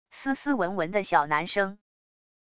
斯斯文文的小男生wav音频